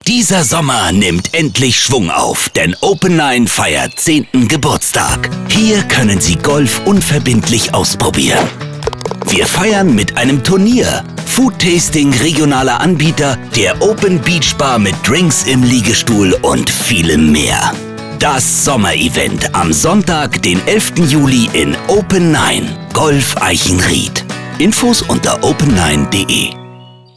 Hier hört Ihr wie die ROCKANTENNEunseren Geburtstag ankündigt:
Werbespot